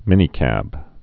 (mĭnē-kăb)